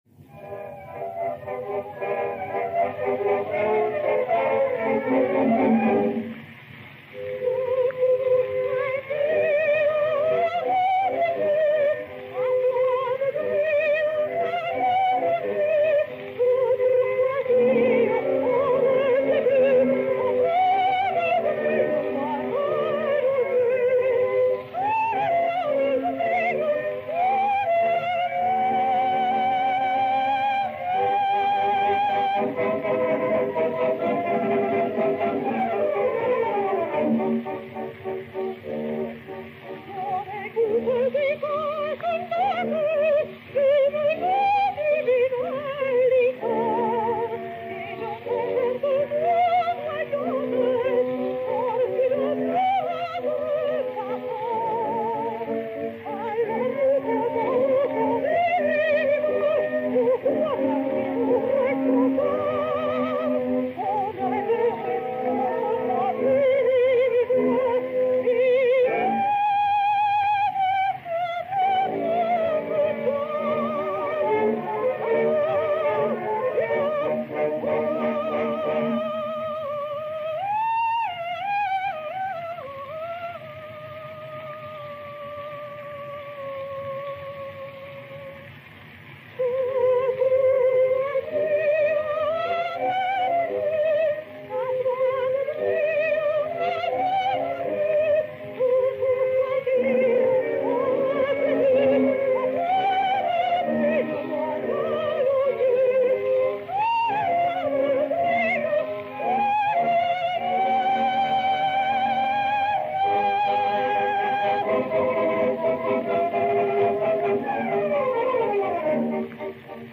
et Orchestre